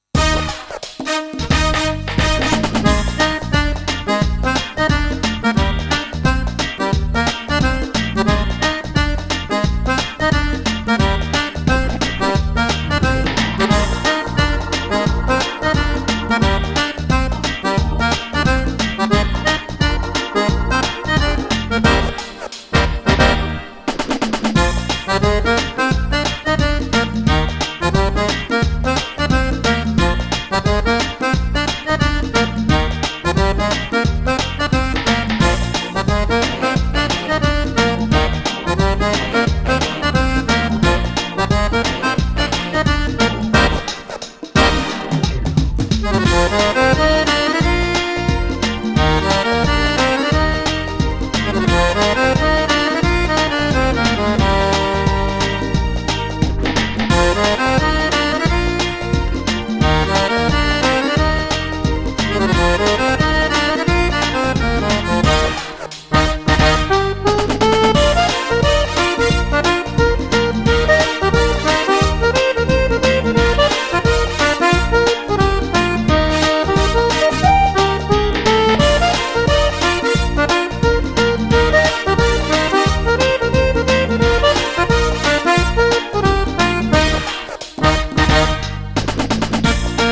14 Brani per Fisarmonica solista e orchestra.
Batteria
Sax
Tastiere e chitarre.